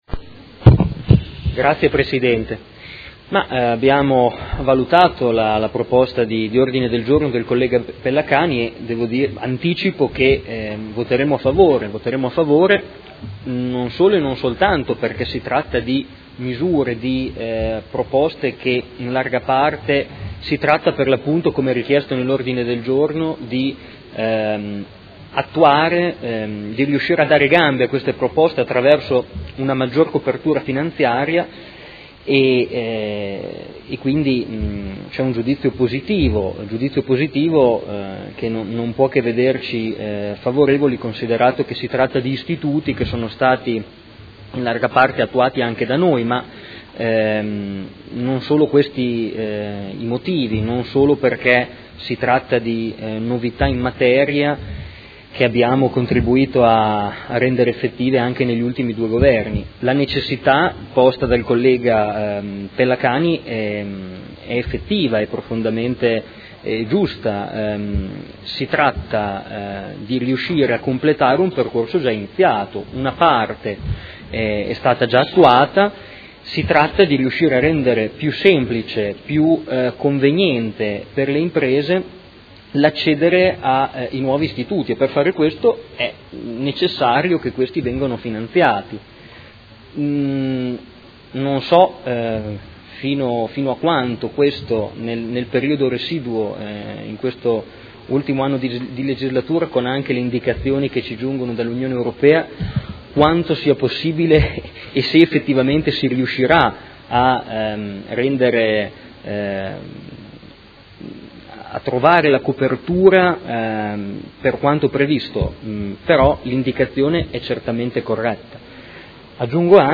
Marco Forghieri — Sito Audio Consiglio Comunale